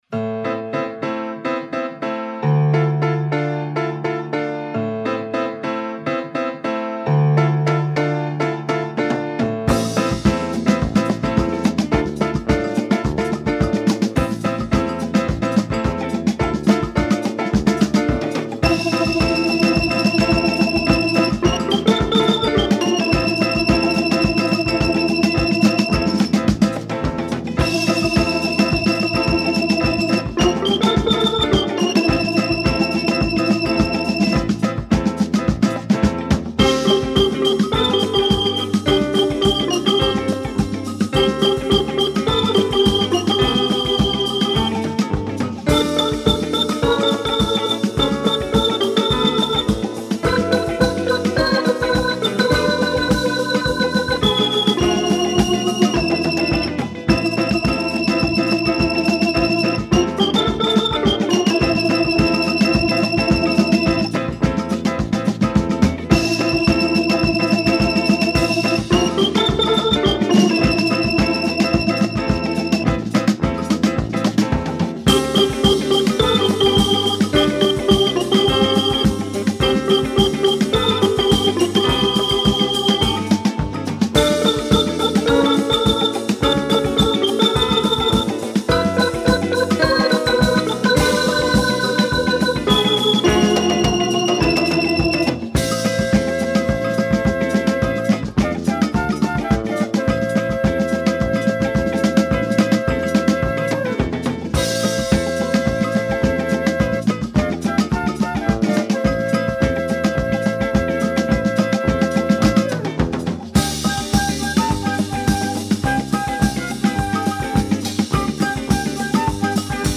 как раз "а-ля" бразильскую